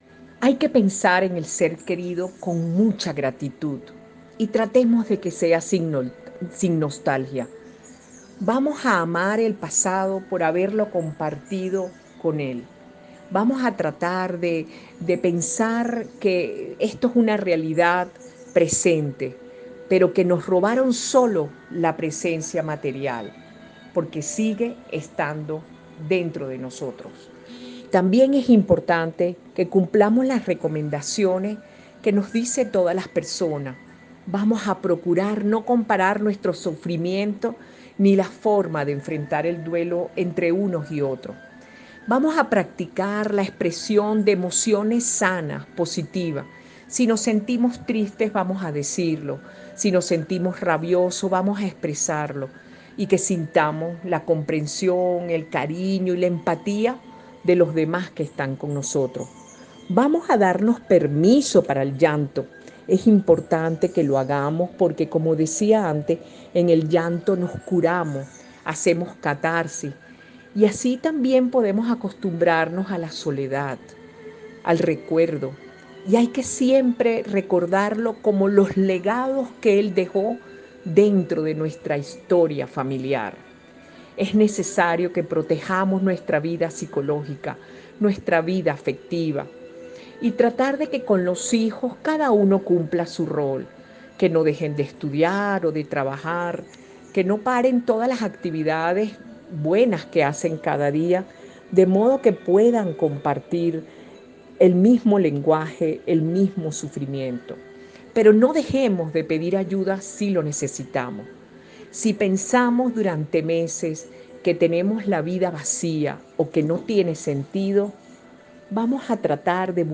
A continuación les presentamos los audios con su ponencia.